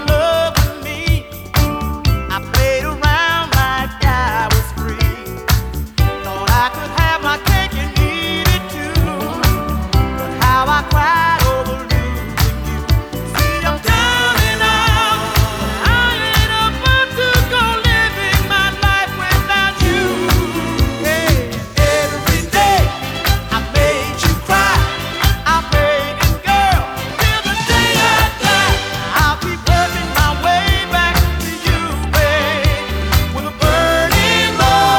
Жанр: Поп музыка / R&B / Соул / Диско